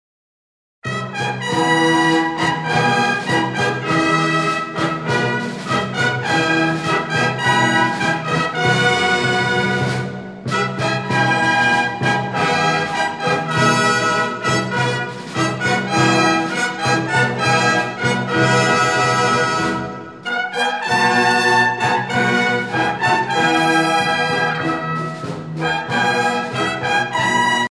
sonnerie réglementaire - Cérémonial militaire
Pièce musicale éditée